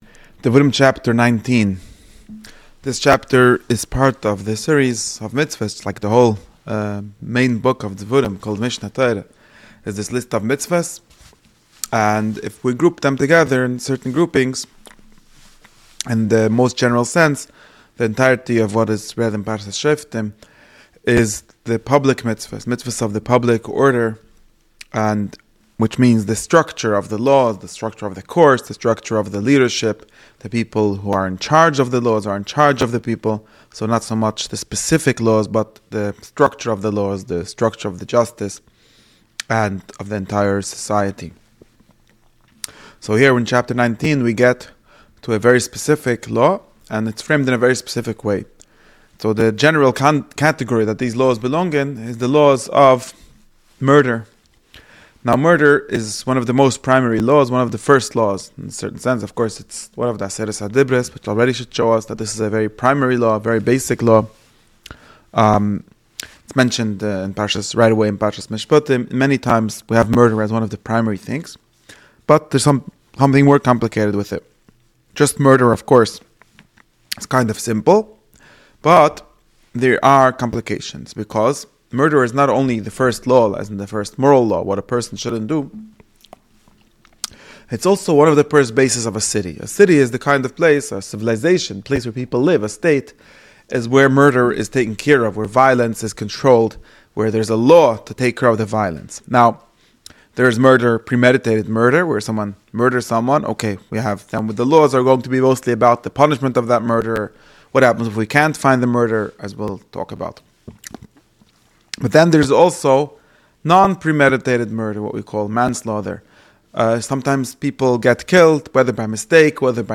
This lecture covers Deuteronomy Chapter 19, focusing on the cities of refuge, boundary laws, and witness testimony.